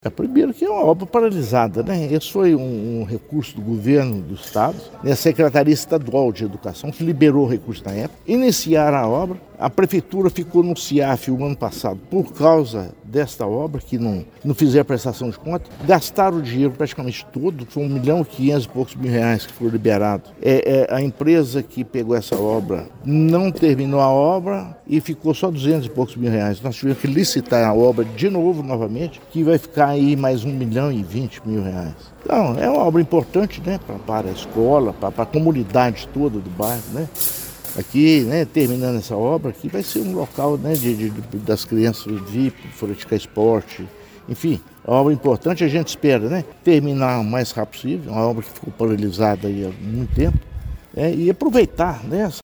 O prefeito Inácio Franco detalhou que a obra havia sido paralisada anteriormente devido a falhas na prestação de contas de recursos estaduais e ao abandono por parte da empresa que venceu a primeira licitação.